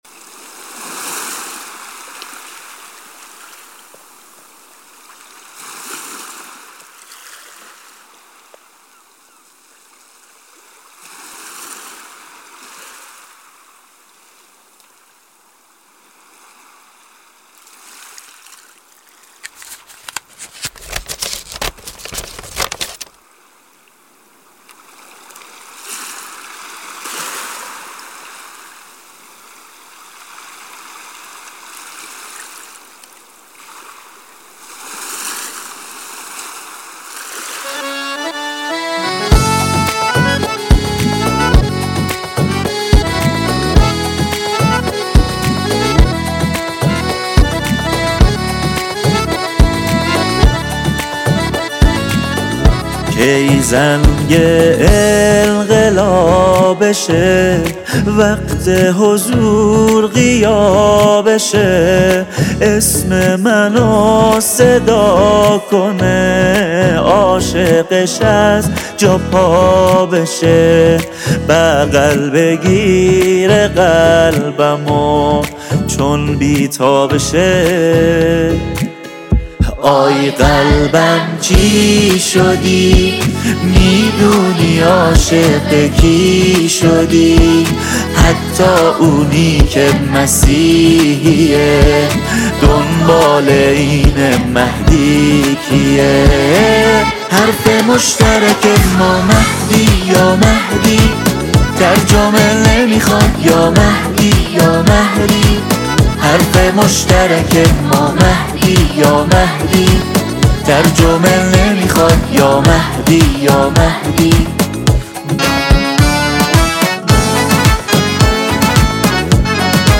فضایی صمیمی و معنوی
سرود ، سرود انقلابی ، سرود مذهبی ، سرود مناسبتی